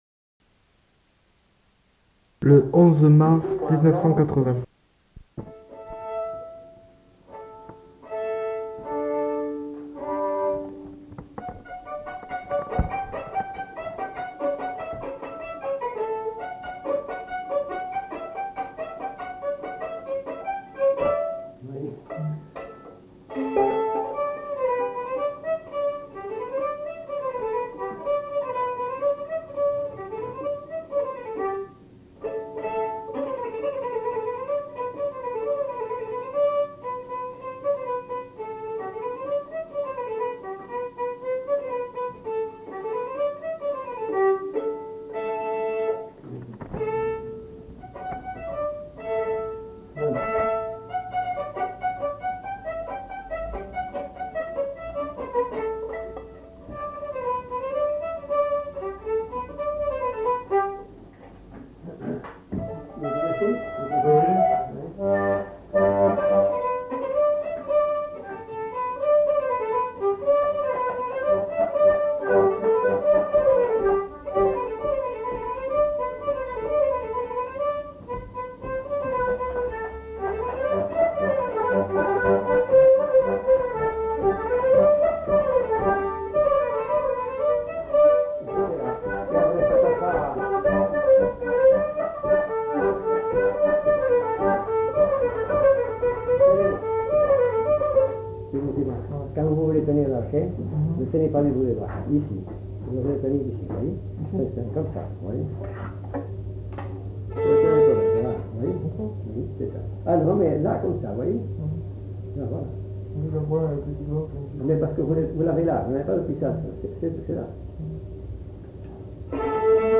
Lieu : Fargues-sur-Ourbise
Genre : morceau instrumental
Instrument de musique : violon ; accordéon diatonique
Danse : congo
Notes consultables : Un des collecteurs accompagne l'interprète à l'accordéon diatonique sur certaines parties.